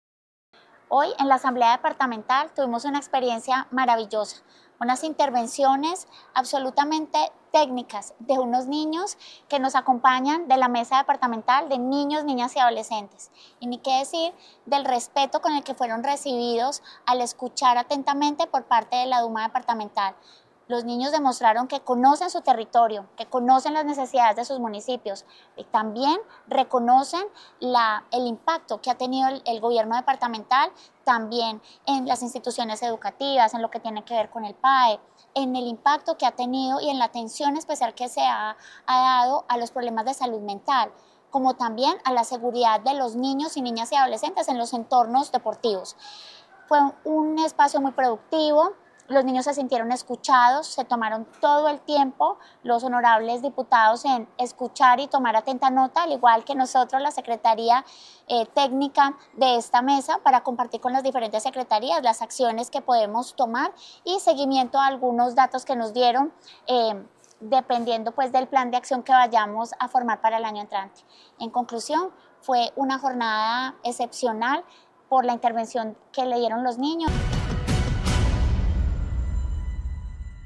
Sandra Patricia Álvarez Castro, secretaria de Integración y Desarrollo Social.